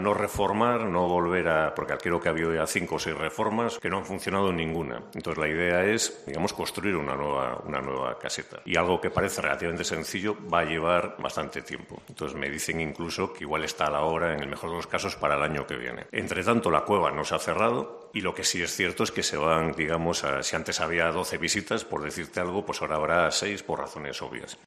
González se ha pronunciado así a preguntas de los medios en la rueda de prensa de presentación de la quinta edición de Camino Escena Norte.